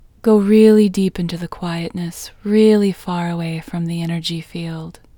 OUT Technique Female English 11